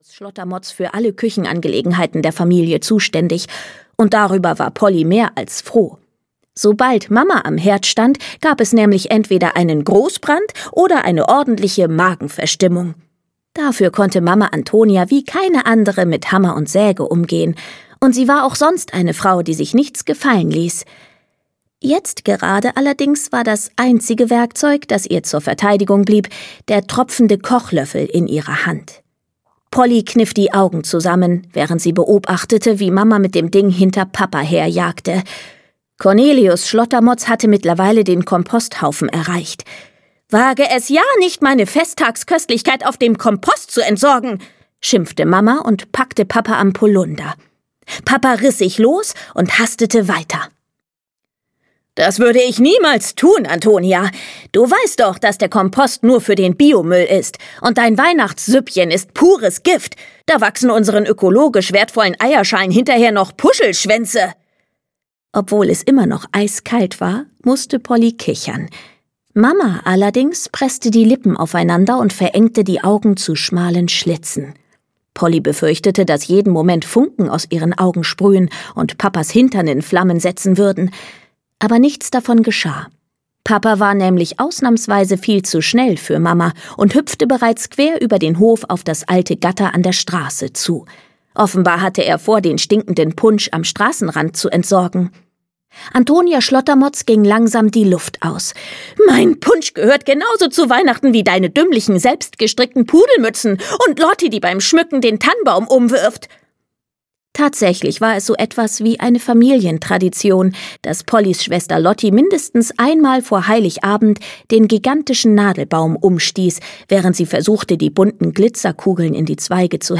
Polly Schlottermotz: Juchee – Weihnachten im Schnee! - Lucy Astner - Hörbuch